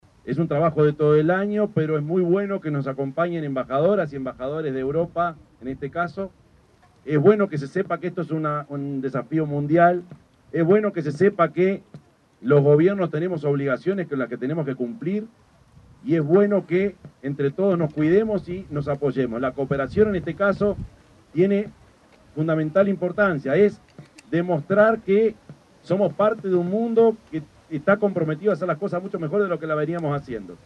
yamandu_orsi_intendente_de_canelones_11.mp3